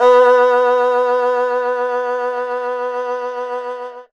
52-bi01-erhu-f-b2.wav